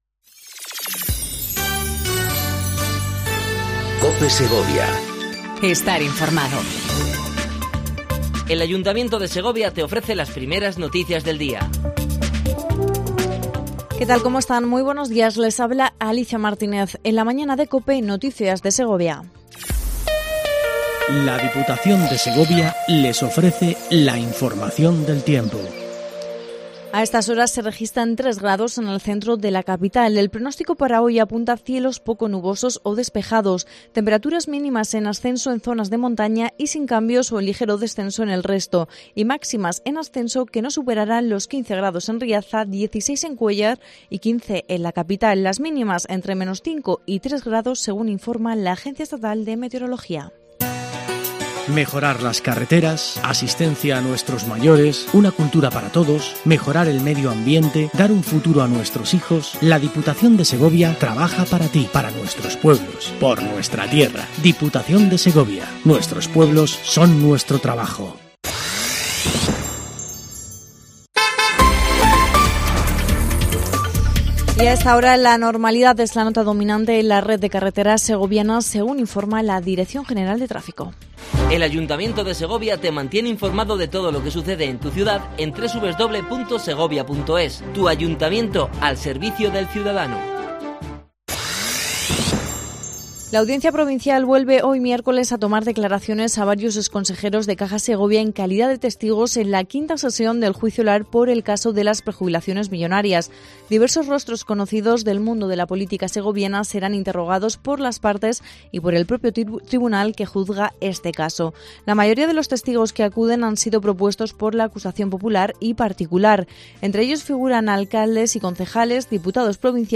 AUDIO: Primer informativo local en cope segovia ¡FELIZ DÍA MUNDIAL DE LA RADIO!